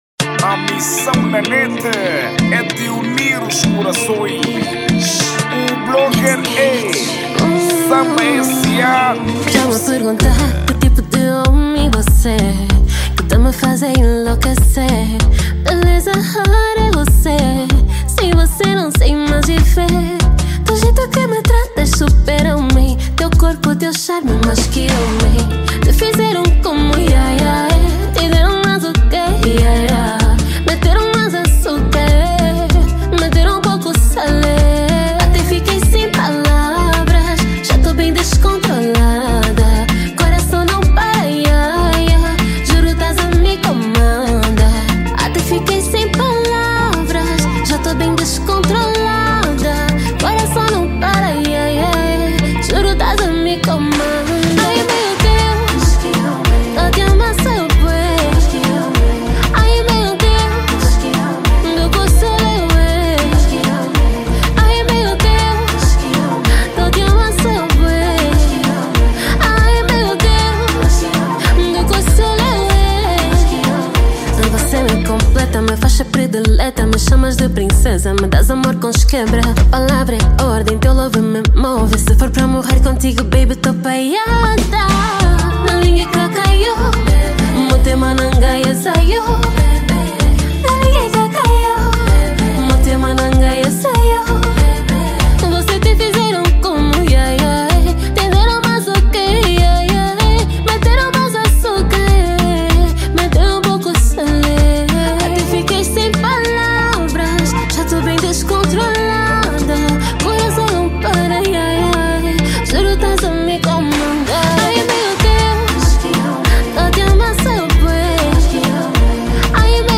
| Kizomba